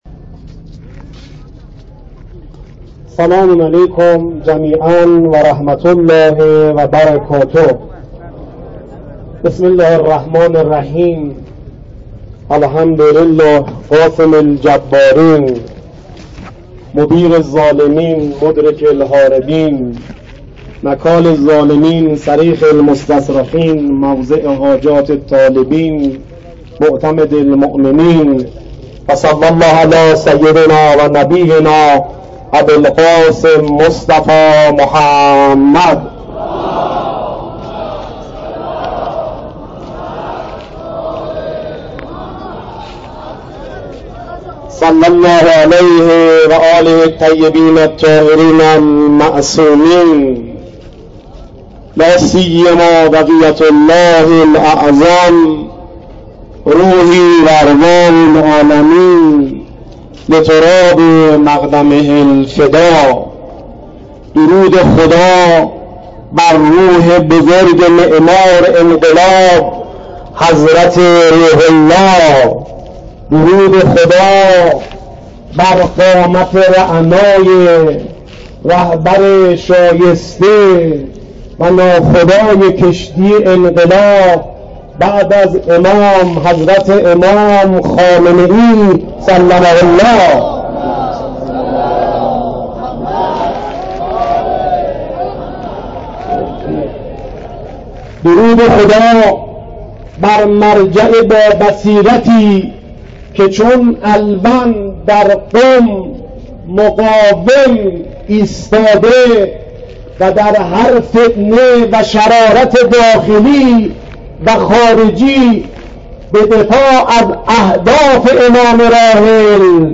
سخنرانی
وعظ و خطابه